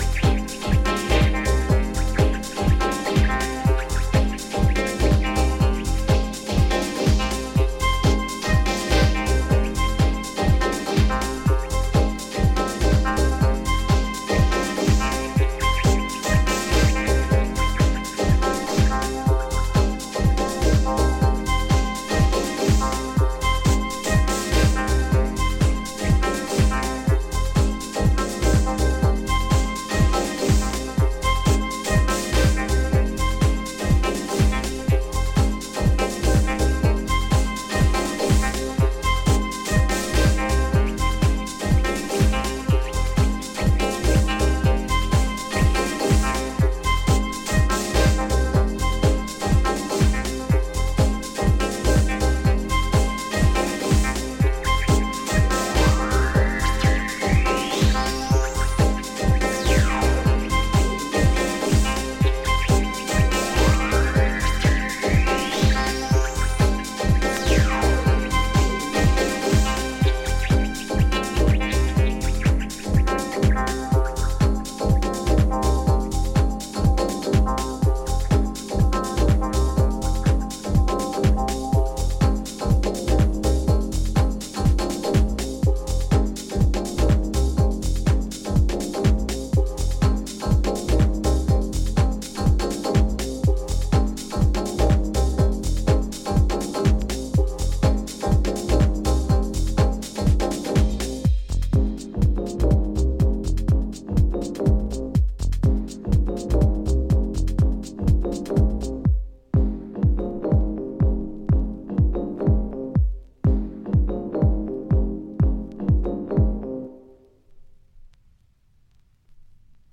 house that is deep but seriously musical and classy
spine-tingling vocal soul
’ which taps into classic early Chicago sounds.
a balmy follow up with painterly synths and persuasive stabs